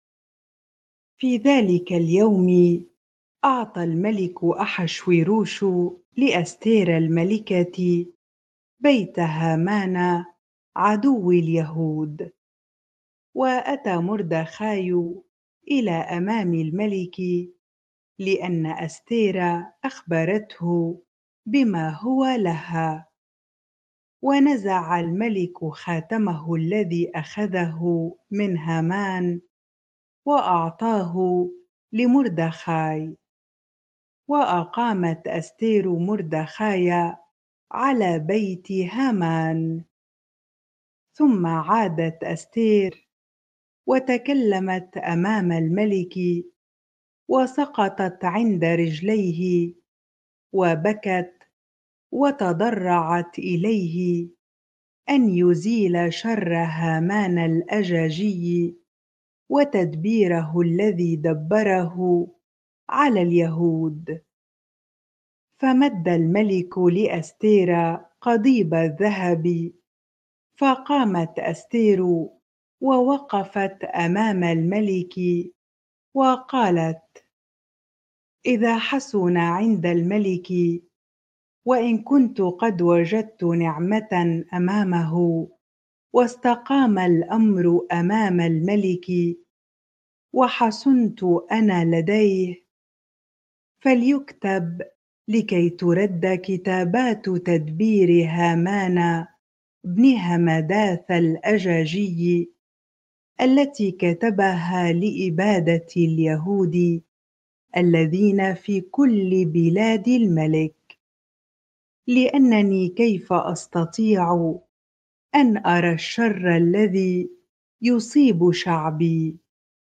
bible-reading-Esther 8 ar